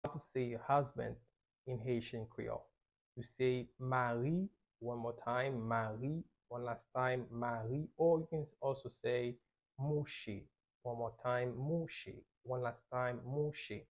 How to say Husband in Haitian Creole - Mari pronunciation by a native Haitian Teacher
Listen to “Mari” or “Mouche” Pronunciation in Haitian Creole by a native Haitian can be heard in the audio here or in the video below:
How-to-say-Husband-in-Haitian-Creole-Mari-pronunciation-by-a-native-Haitian-Teacher.mp3